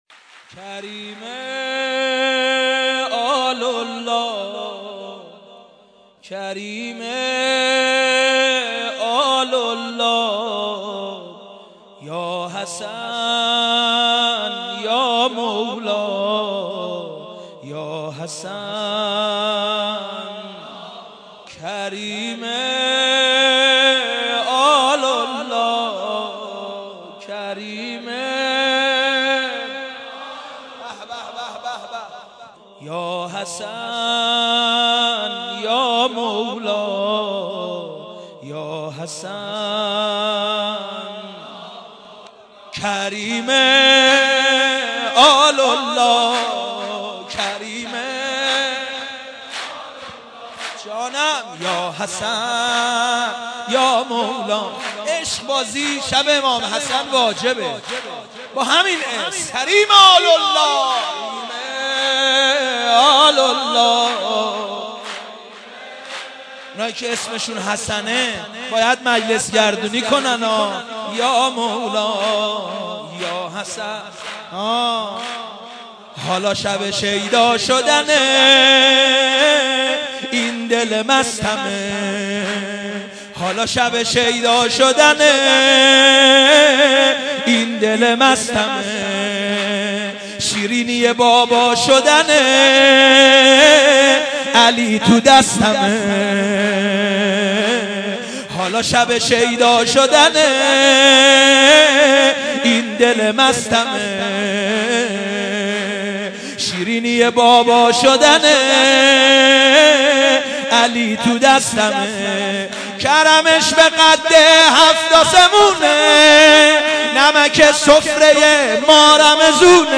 • مولودی ویژه میلاد امام حسن مجتبی علیه السلام
مداح